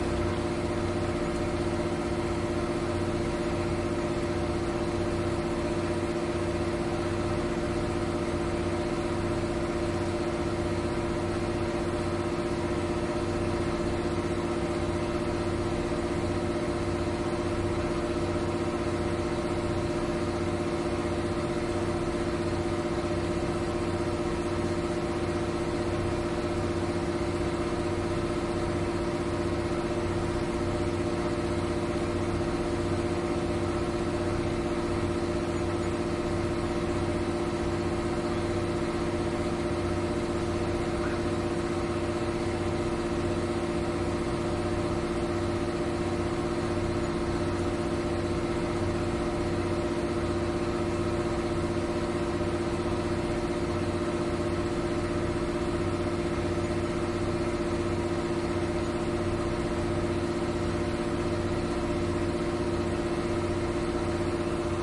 亚马逊 " 船亚马逊渡轮2层柴油驳船上的响声1
描述：船亚马逊渡轮2甲板柴油驳船大声
Tag: 驳船 柴油 机载 轮渡